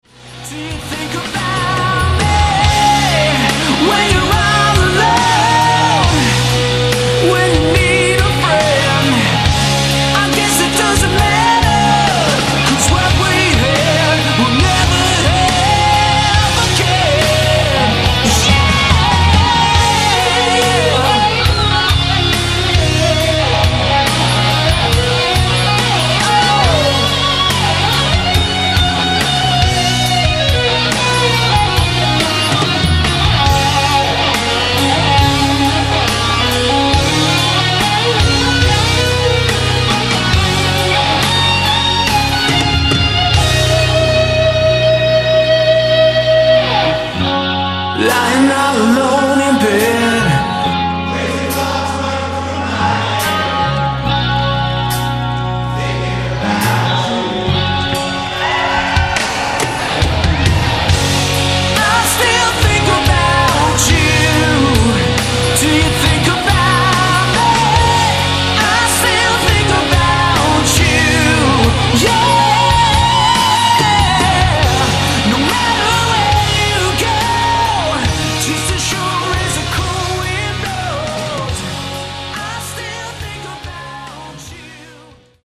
vox
bass
drums
guitar
Recorded live on tour in 2003.